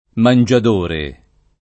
mangiadore [ man J ad 1 re ] → mangiatore